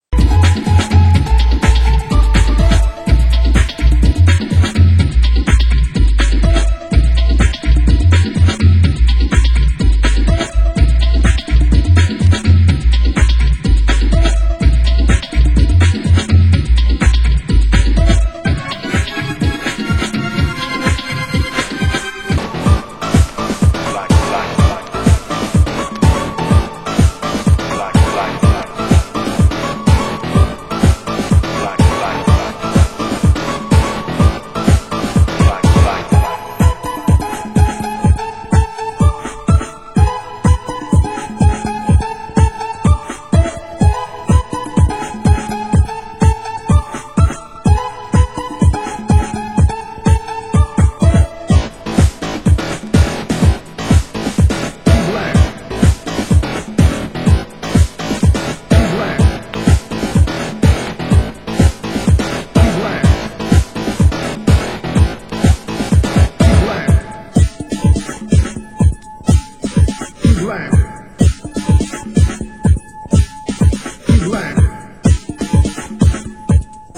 Genre: Hardcore